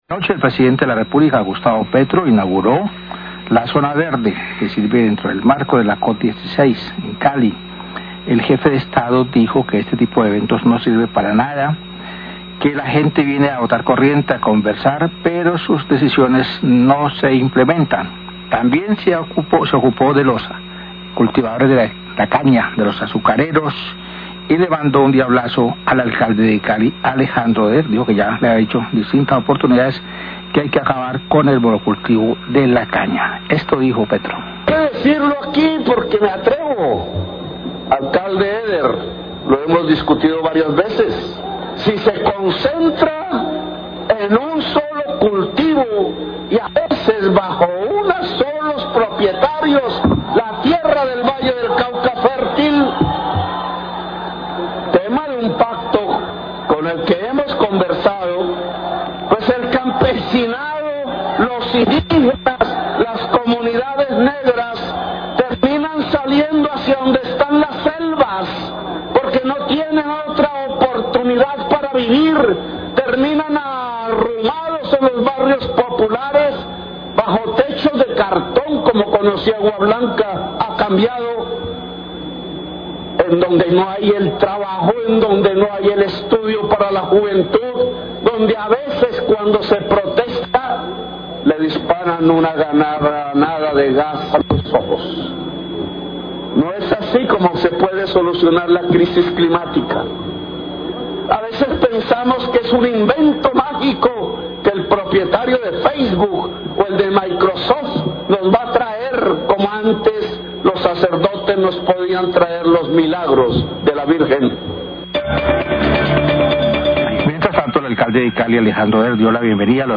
NOTICIERO RELÁMPAGO
La mesa de trabajo opinó acerca de la intervención del presidente.